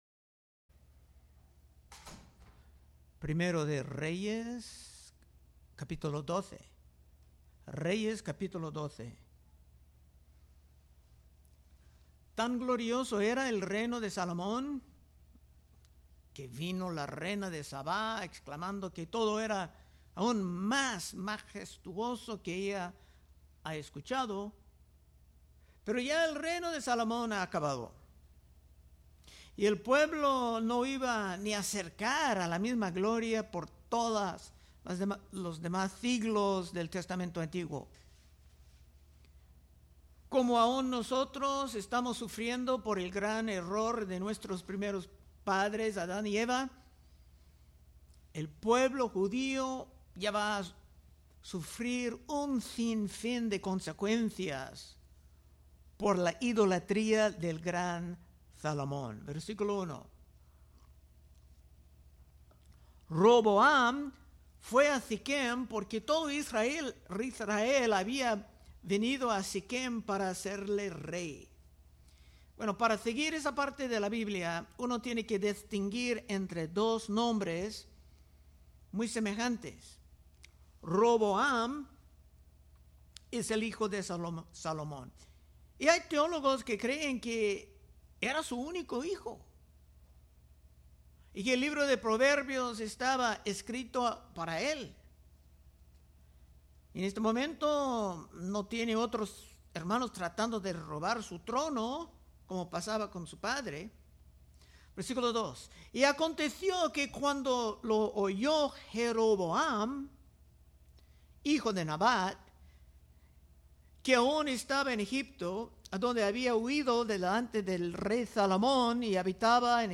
Predicaciones De Exposición Libro De 1 Reyes